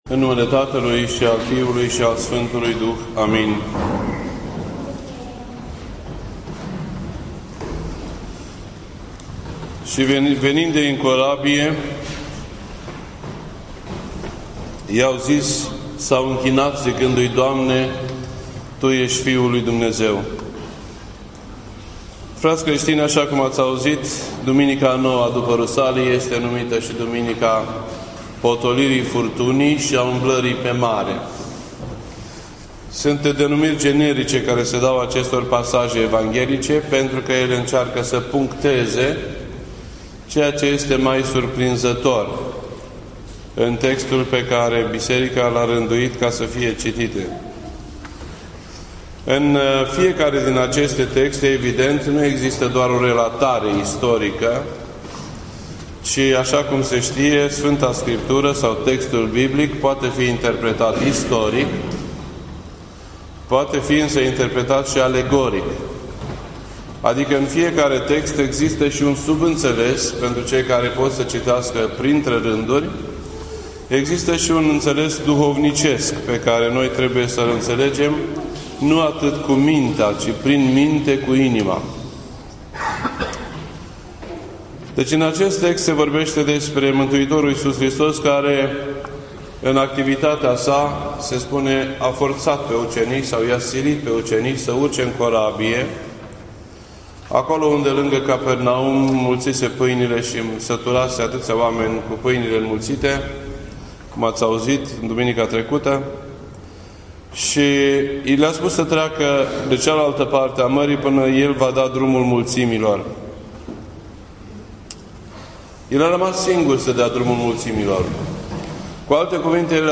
This entry was posted on Sunday, August 2nd, 2015 at 6:08 PM and is filed under Predici ortodoxe in format audio.